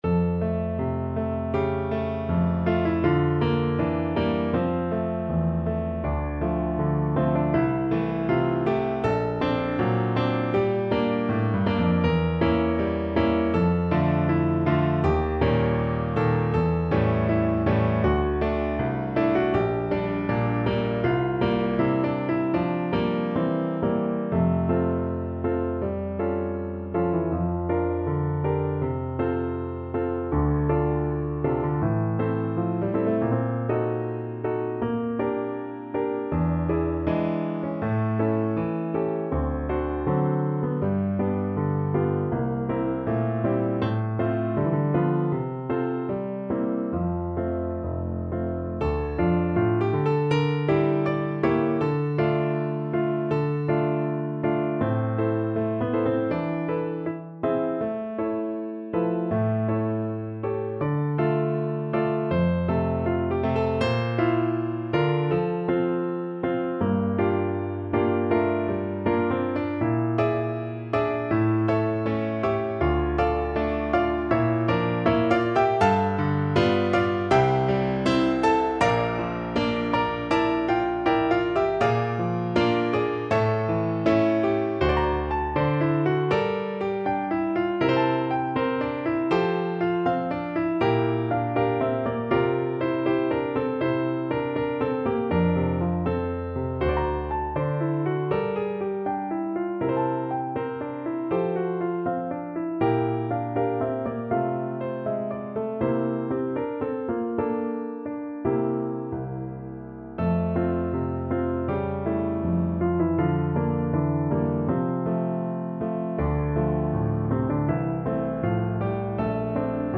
Classical Merikanto, Oskar Kesäaamu (Idyll) Piano version
No parts available for this pieces as it is for solo piano.
4/4 (View more 4/4 Music)
F major (Sounding Pitch) (View more F major Music for Piano )
Moderato, tranquillamente =80
Piano  (View more Advanced Piano Music)
Classical (View more Classical Piano Music)